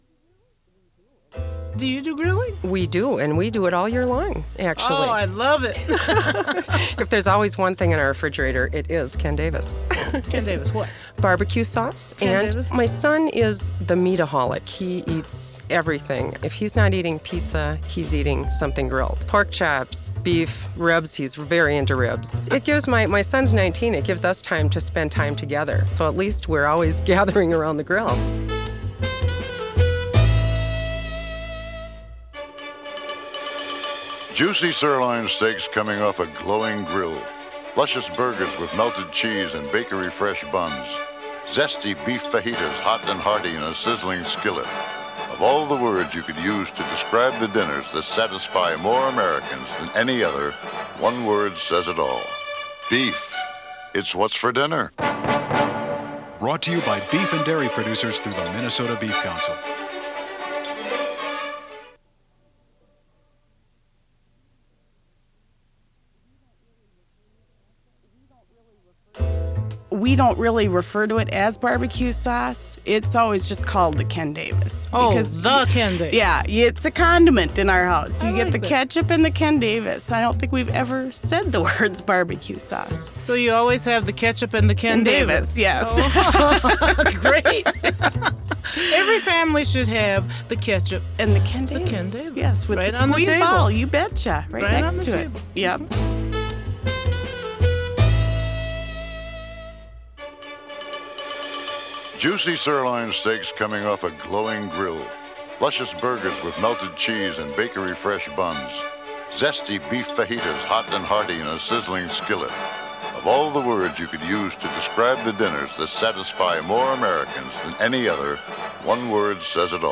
Radio advertisements,
Cook House Recording Studio.